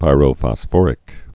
(pīrō-fŏs-fôrĭk)